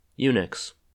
Unix (/ˈjnɪks/
, YOO-niks; trademarked as UNIX) is a family of multitasking, multi-user computer operating systems that derive from the original AT&T Unix, whose development started in 1969[1] at the Bell Labs research center by Ken Thompson, Dennis Ritchie, and others.[4] Initially intended for use inside the Bell System, AT&T licensed Unix to outside parties in the late 1970s, leading to a variety of both academic and commercial Unix variants from vendors including University of California, Berkeley (BSD), Microsoft (Xenix), Sun Microsystems (SunOS/Solaris), HP/HPE (HP-UX), and IBM (AIX).
En-us-Unix.oga.mp3